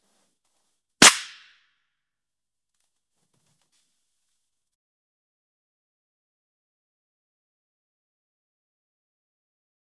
Звук электрошокера